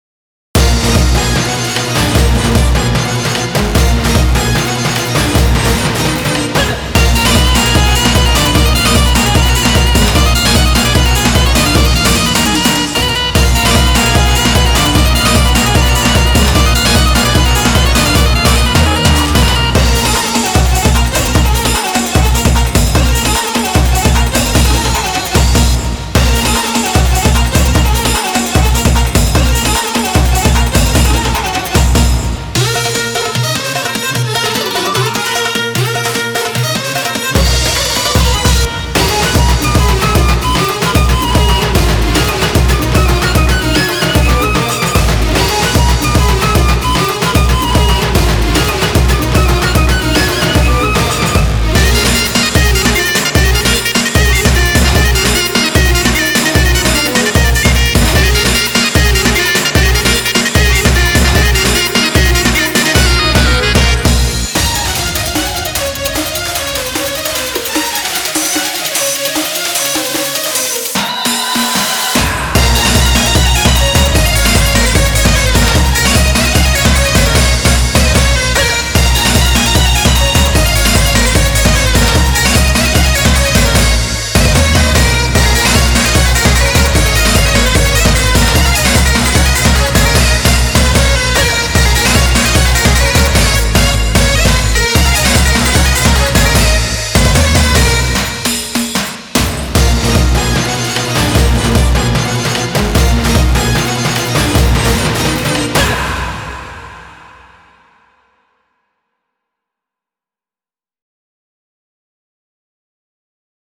BPM150
Audio QualityPerfect (High Quality)
An intense Egyptian-like song